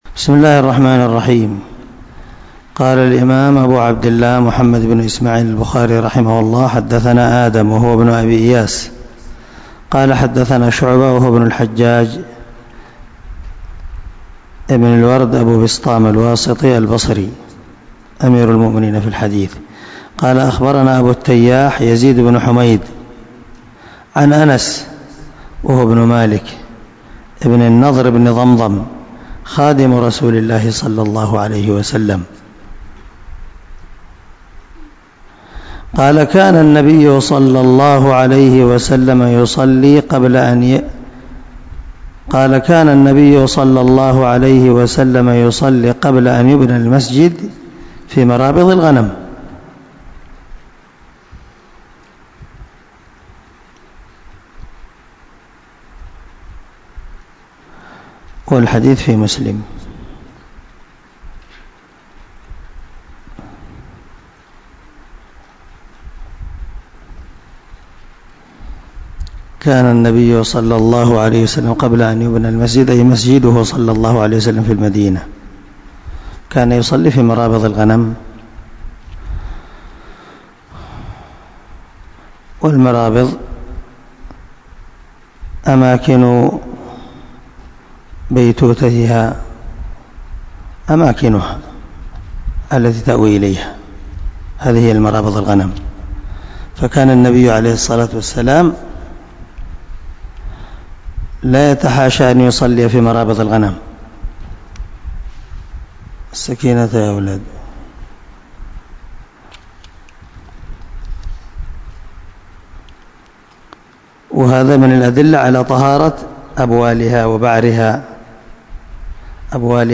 196الدرس 72 من شرح كتاب الوضوء حديث رقم ( 234 ) من صحيح البخاري